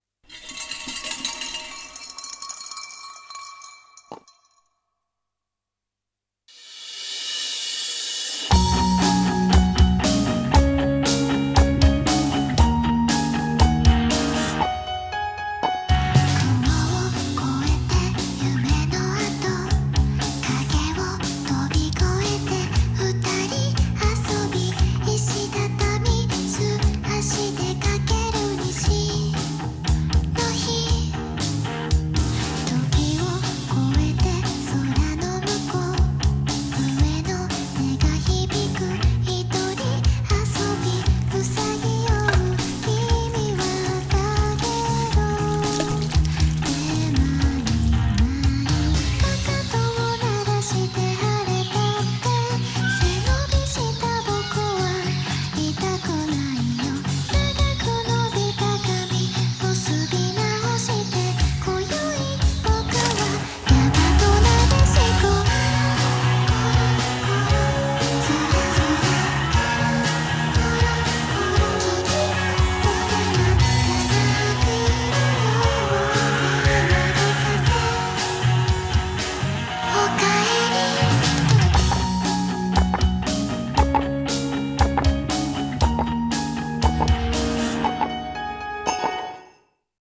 CM風声劇「懐古神社」